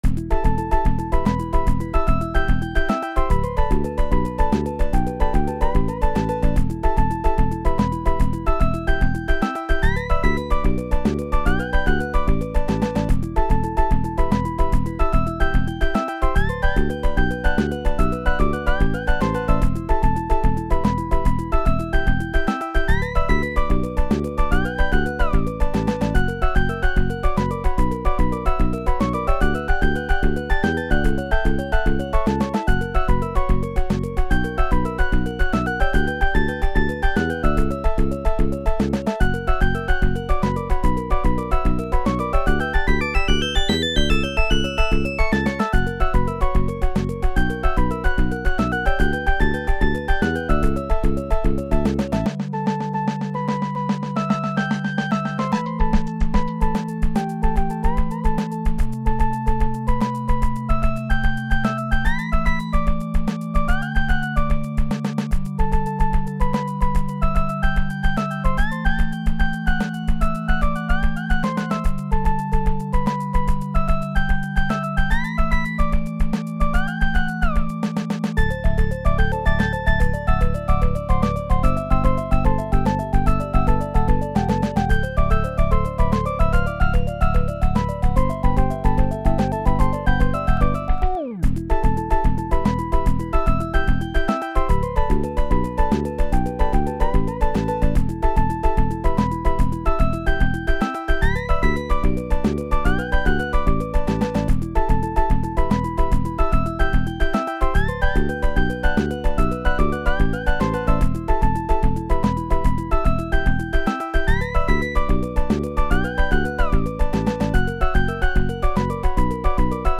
Puppy Playing in The Garden SNES PORT
SNES is one of the hardest formats to write music for.